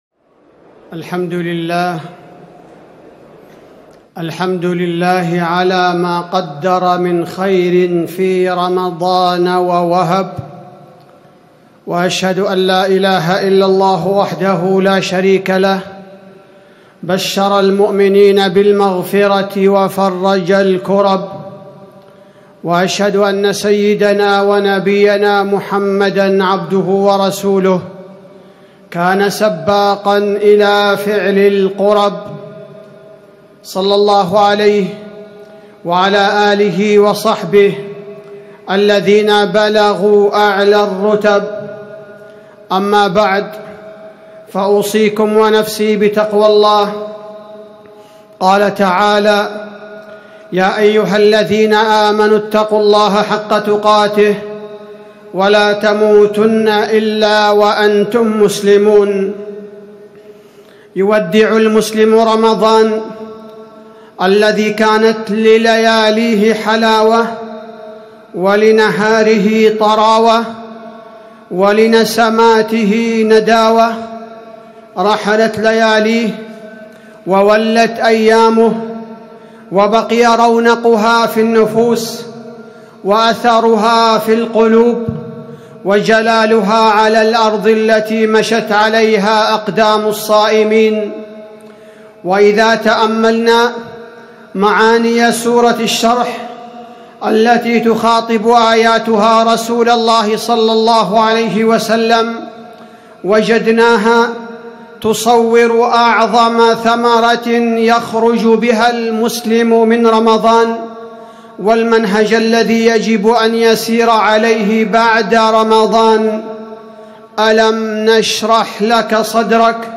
تاريخ النشر ١ شوال ١٤٣٩ هـ المكان: المسجد النبوي الشيخ: فضيلة الشيخ عبدالباري الثبيتي فضيلة الشيخ عبدالباري الثبيتي منهج المسلم بعد رمضان